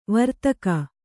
♪ vartaka